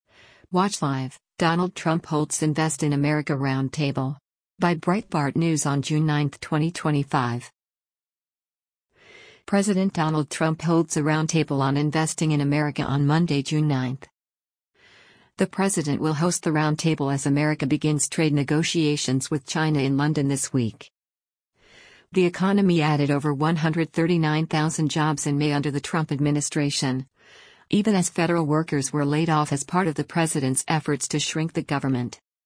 President Donald Trump holds a roundtable on investing in America on Monday, June 9.